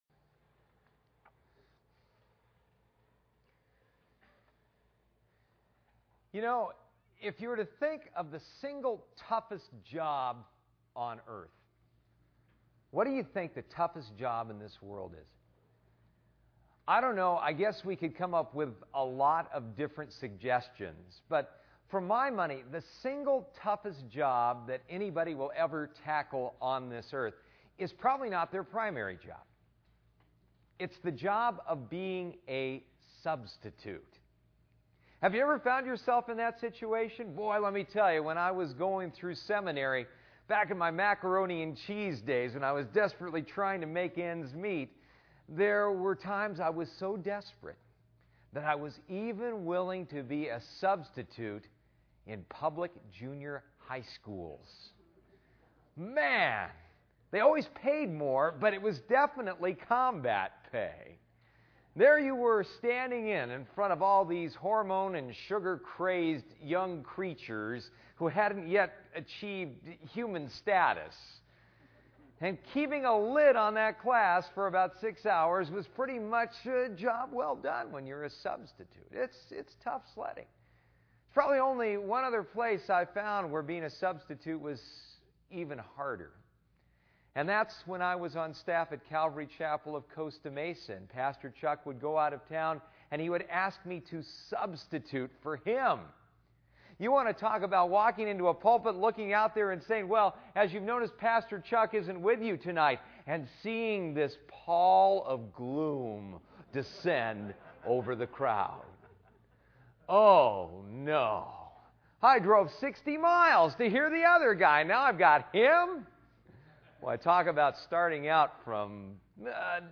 2004 The Substitution Solution Preacher